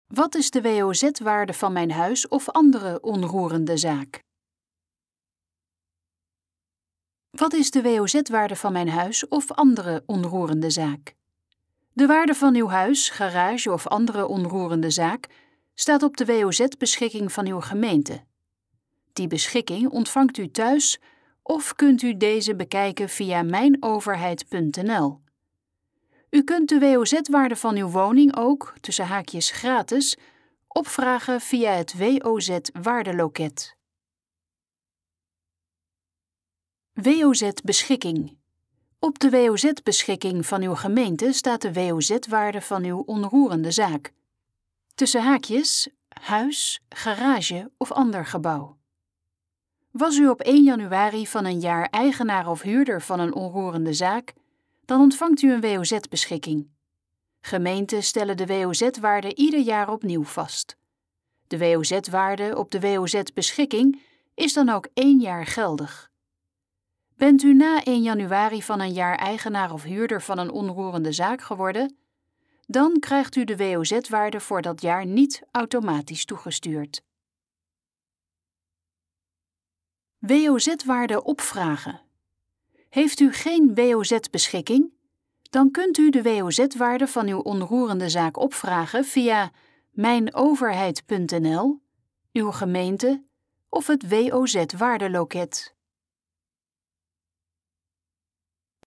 Dit geluidsfragment is de gesproken versie van de pagina Wat is de WOZ-waarde van mijn huis of andere onroerende zaak?